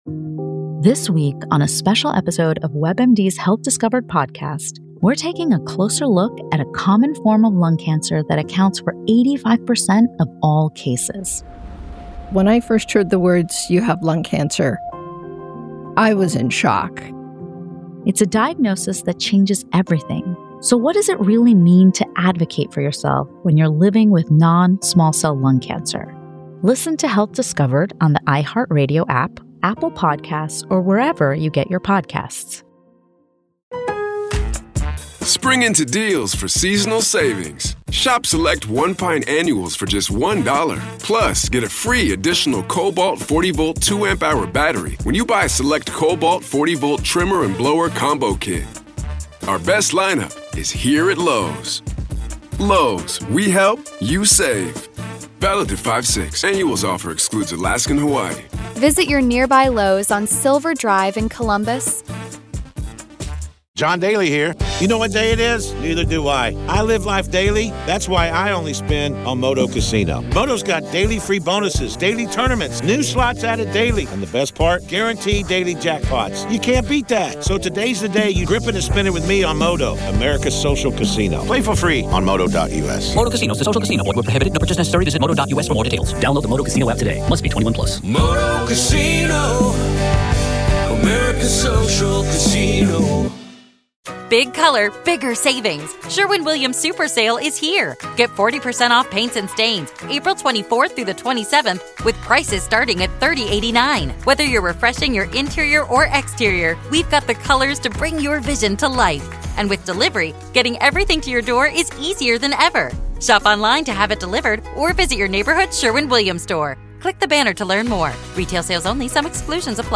This conversation dives into the unsettling space where faith, fear, and the possibility of demonic influence intersect.